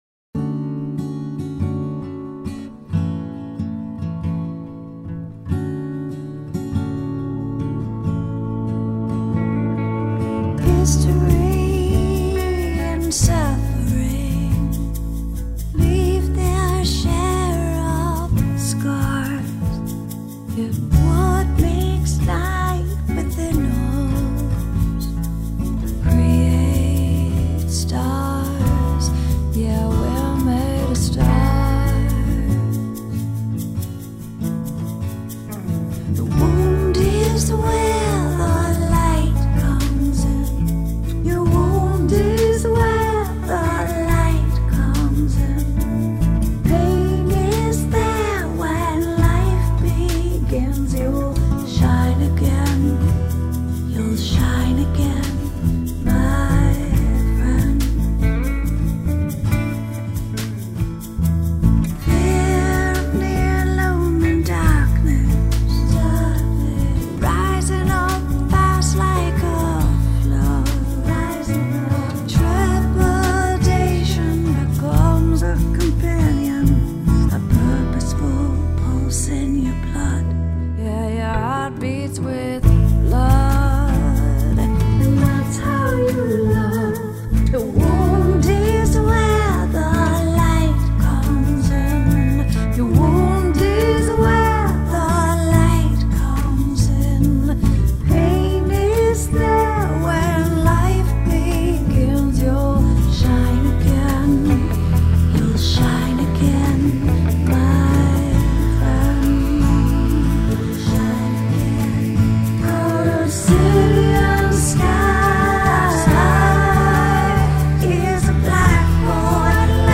70 BPM
Reeled in by the 3/4 feel, can't help but leave orbit.